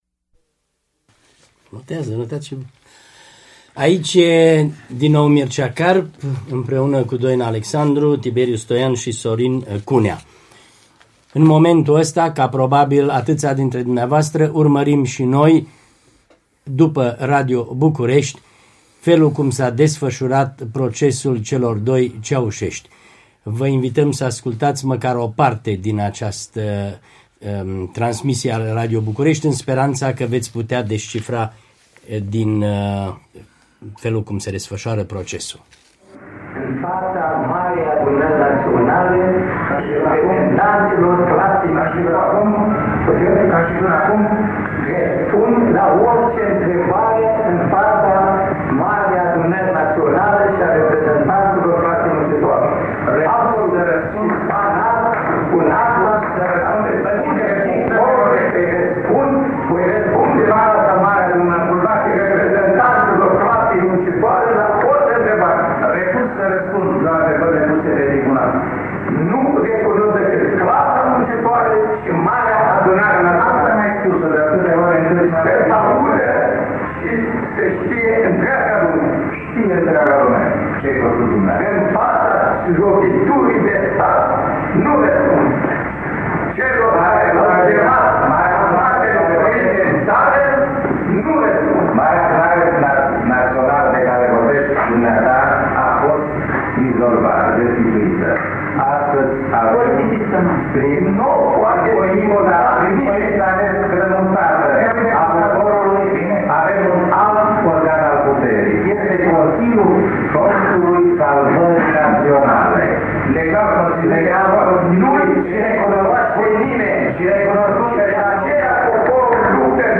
26.12.1989 – Studioul special al „Actulității românești”, fragmente din procesul Ceaușescu